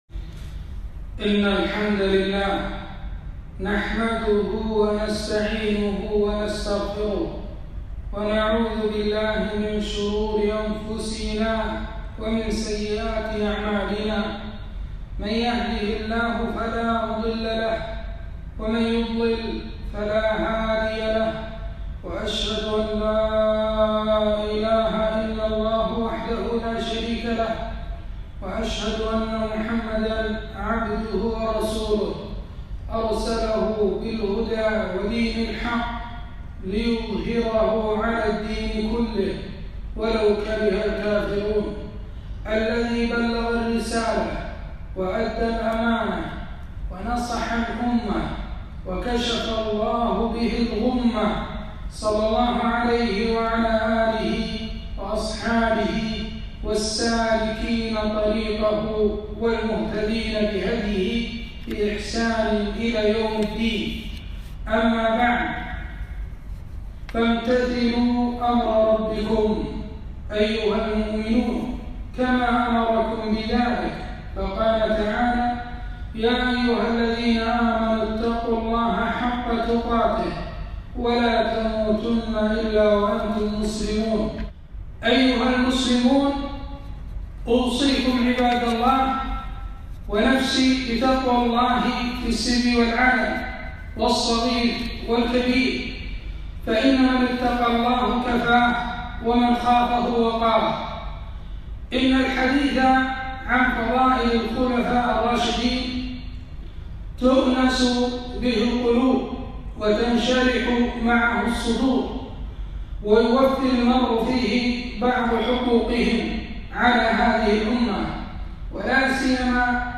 خطبة - علي بن أبي طالب -رضي الله عنه-أمير المؤمنين ورابع الخلفاء الراشدين